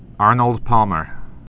(ârnəld pämər, päl-)